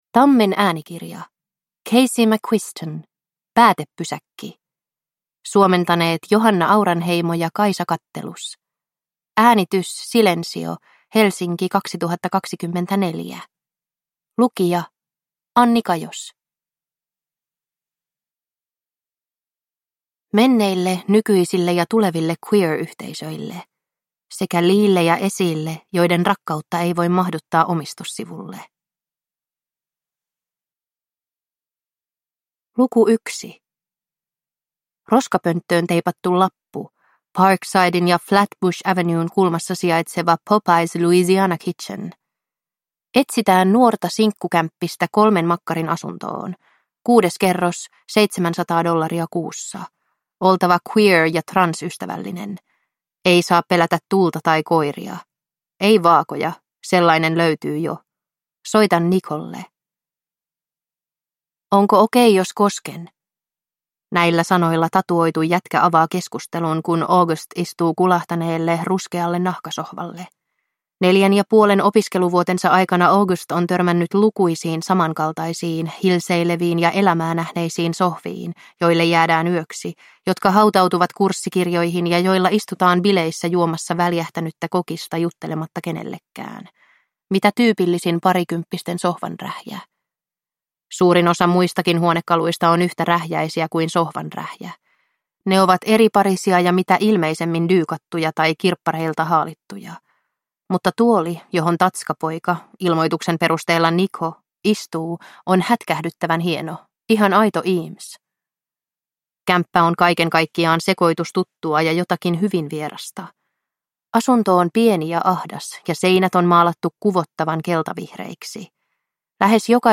Päätepysäkki – Ljudbok